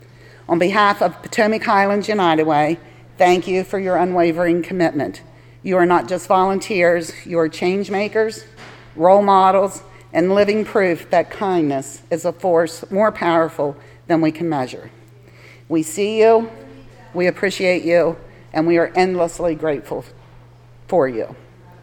The Potomac Highlands United Way hosted a volunteer recognition breakfast at Brookdale farms Tuesday morning.